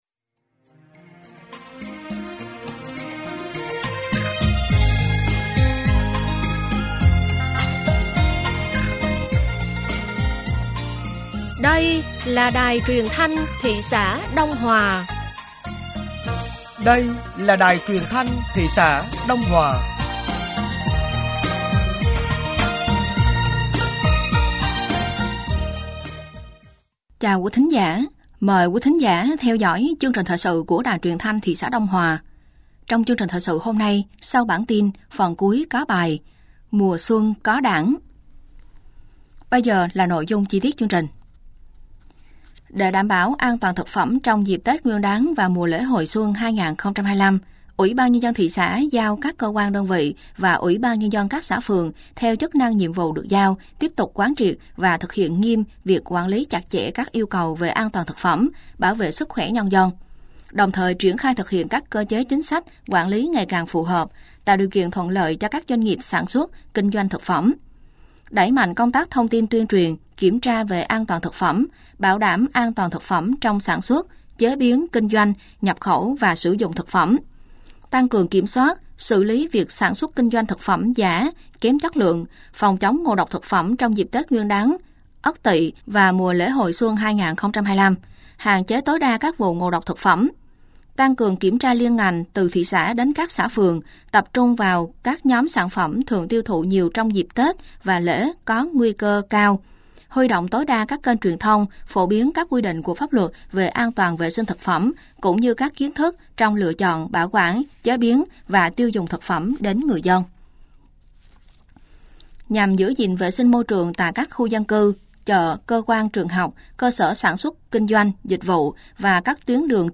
Thời sự tối ngày 26 và sáng ngày 27 tháng 01 năm 2025